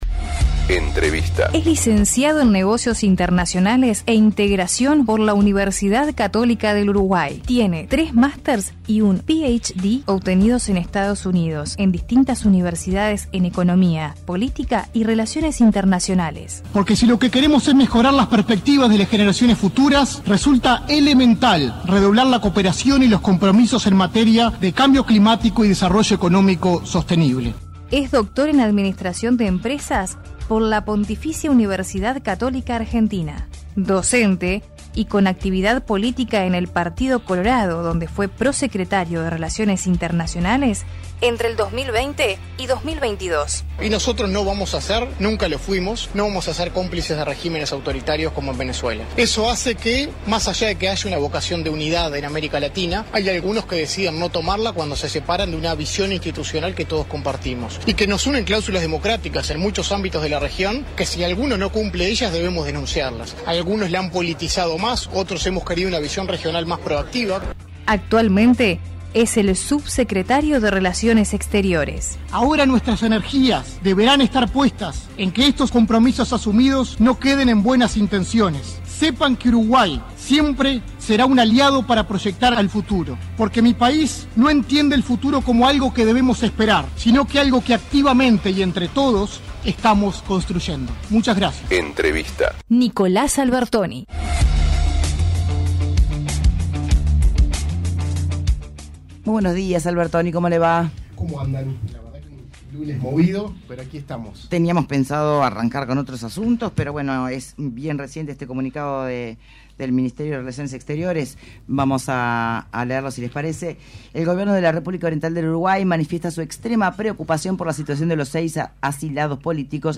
Entrevista completa aquí: El subsecretario de Relaciones Exteriores, Nicolás Albertoni se refirió en Punto de Encuentro a los detalles y las negociaciones de última hora en el acuerdo Unión Europea – Mercosur.